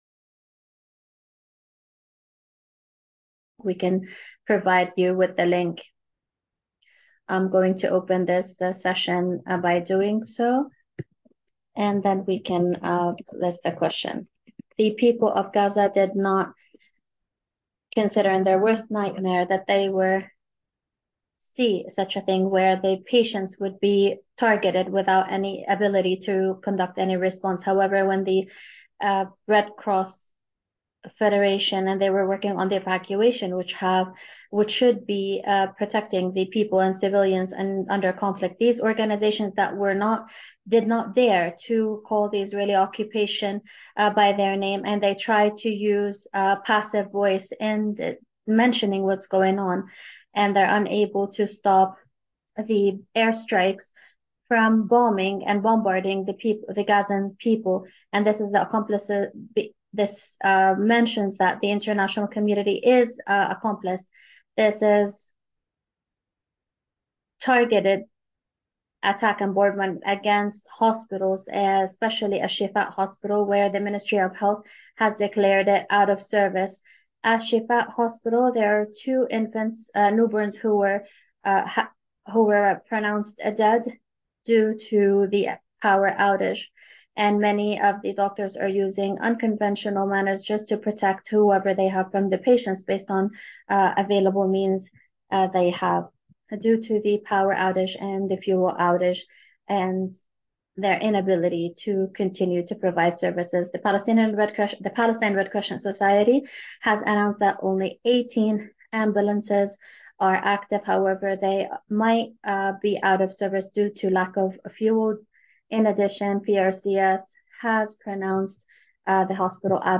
English Interpretation
Panel Discussion